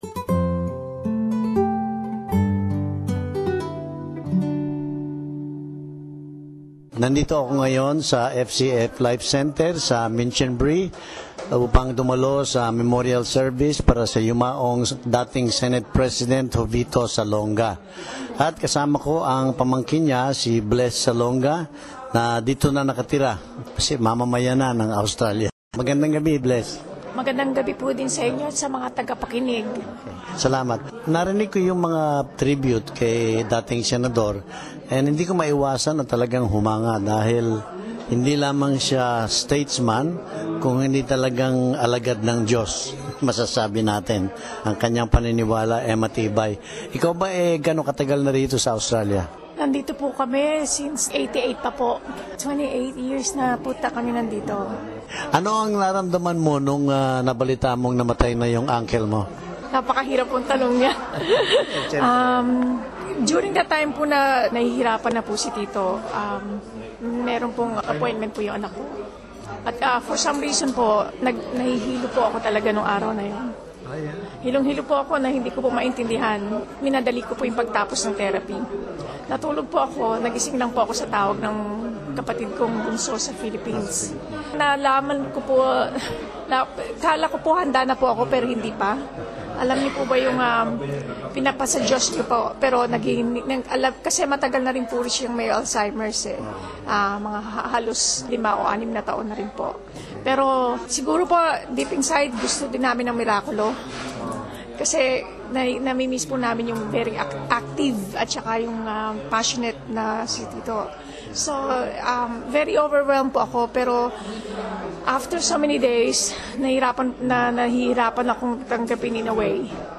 SBS Flipino attended the memorial service in Minchinbury, west of Sydney.